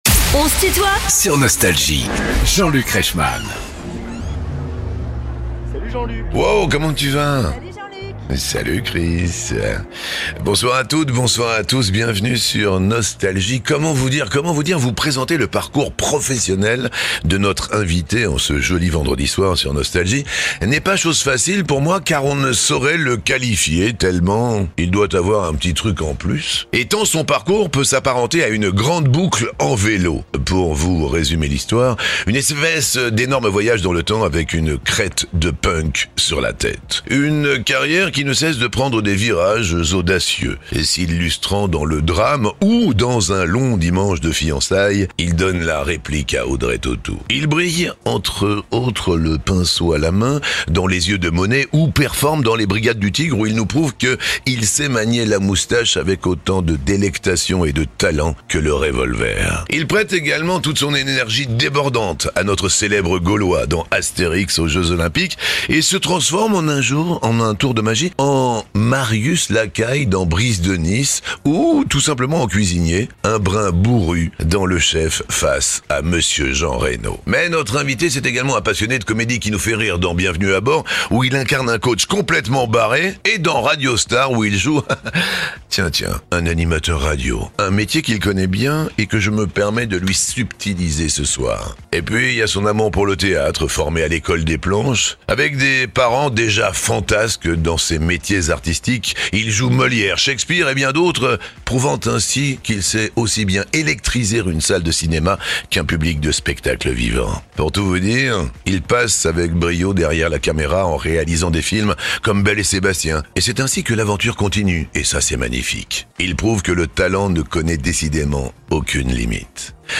Clovis Cornillac est l'invité de "On se tutoie ?..." avec Jean-Luc Reichmann (partie 1) ~ Les interviews Podcast